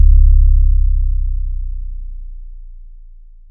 808s
YM Sub 4.wav